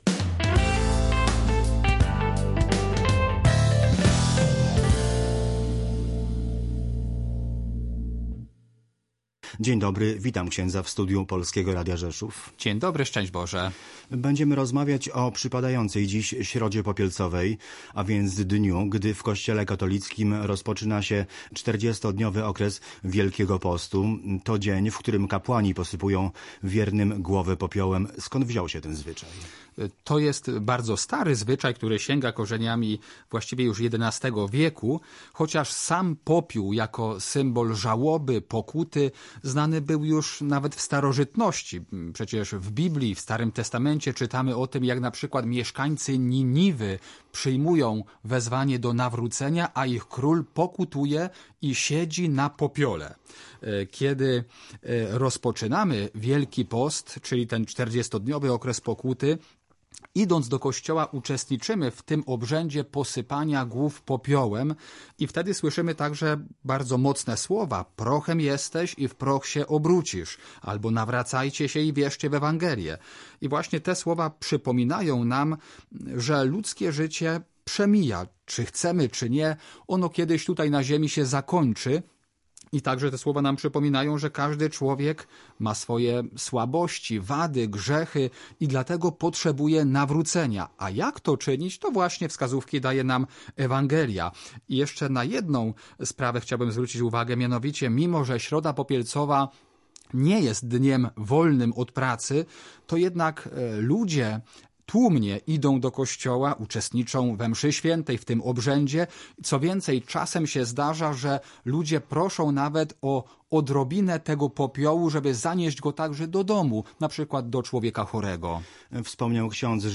Audycje • Dziś Środa Popielcowa.